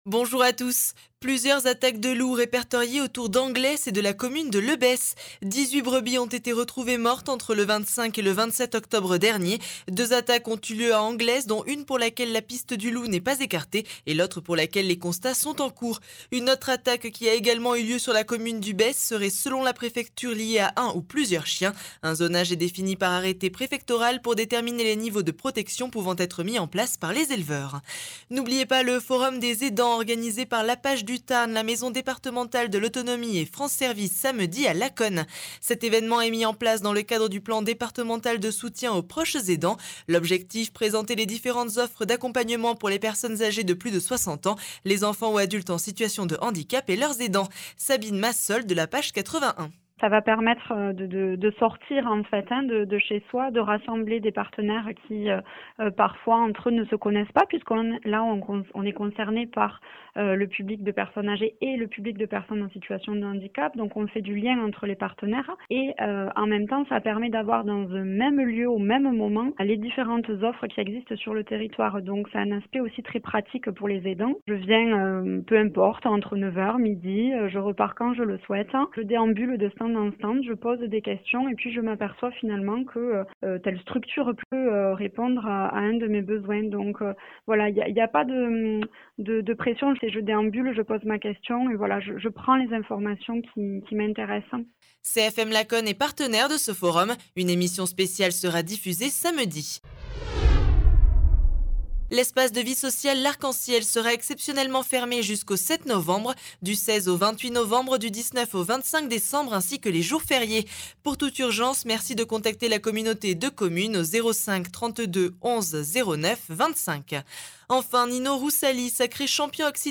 Flash Monts de Lacaune 02 novembre 2022